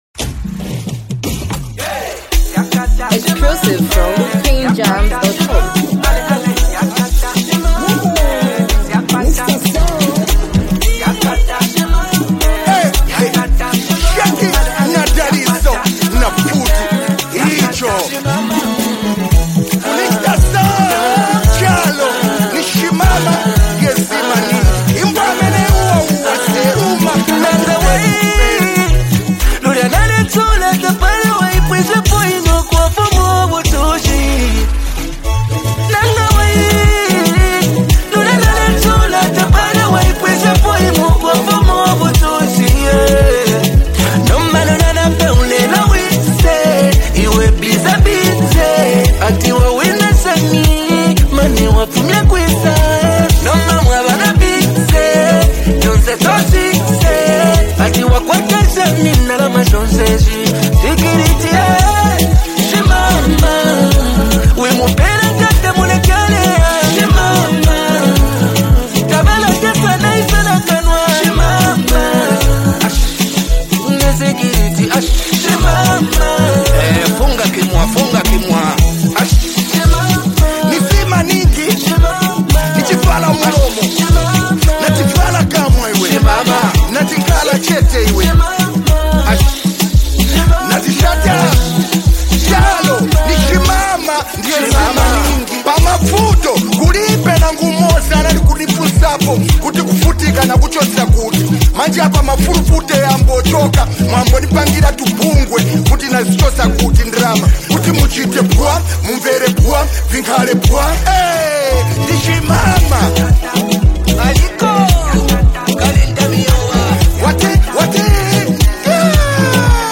smooth vocal delivery adds warmth to the track